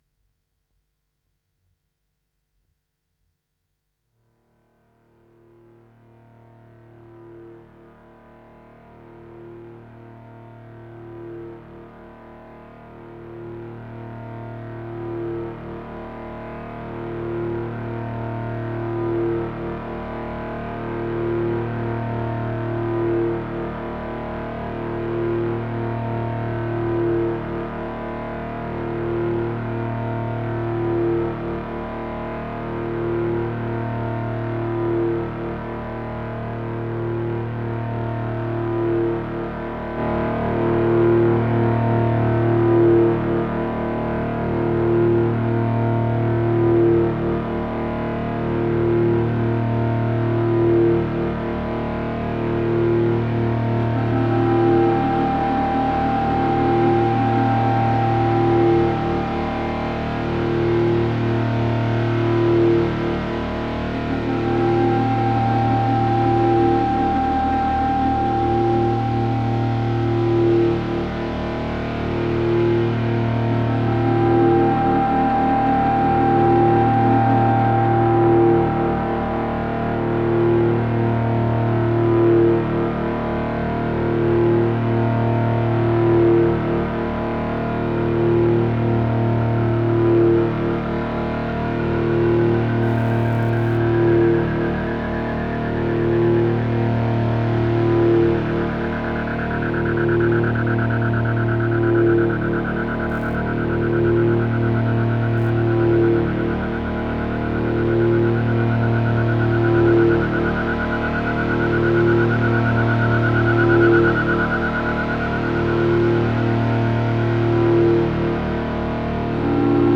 drone solo album
Korg Polysix, Effects